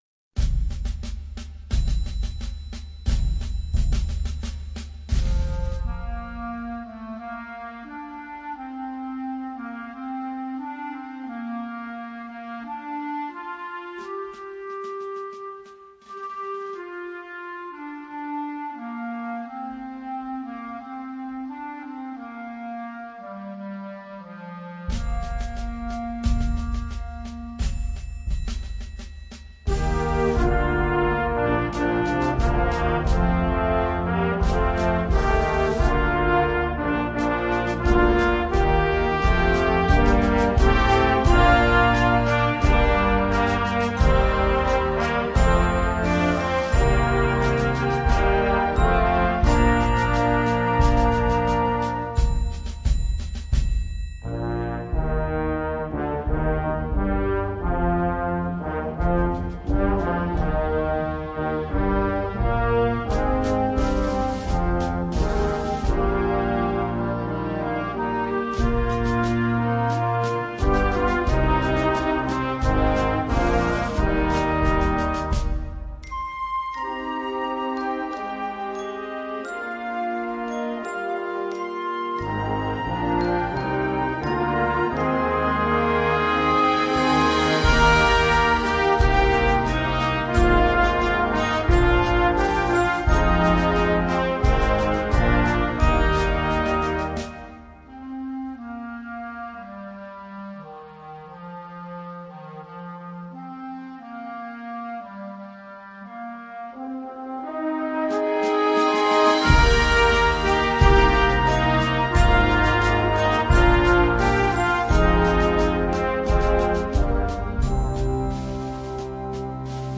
Gattung: Blasmusik für Jugendkapelle - Performer Level
Besetzung: Blasorchester